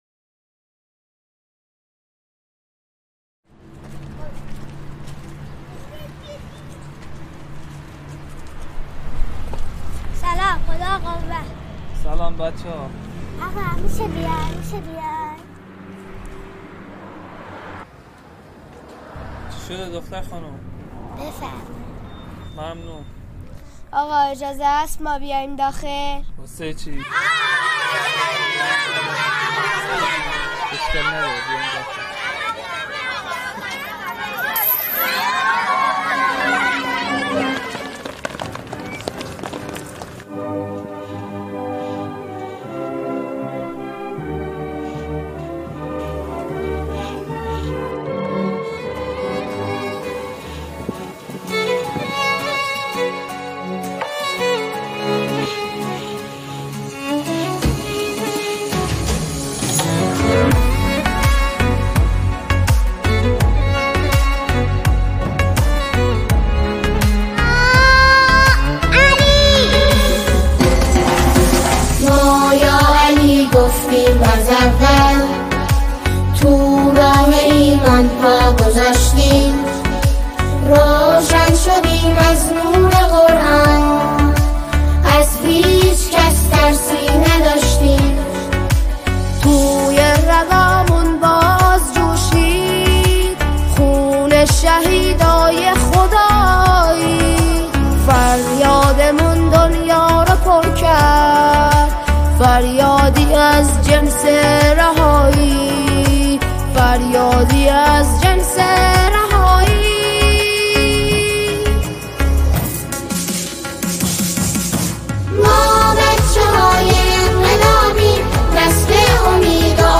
اثری شاد، ساده و صمیمی
ژانر: سرود